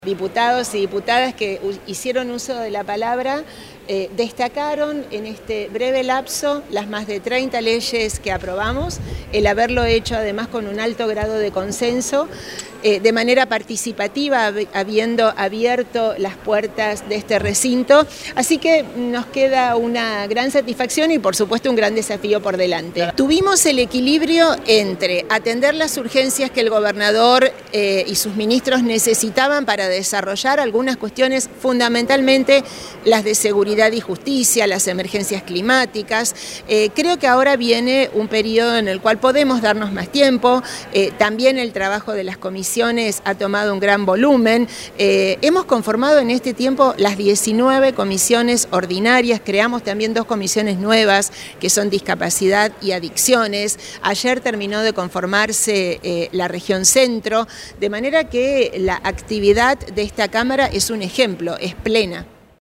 Declaraciones de Clara García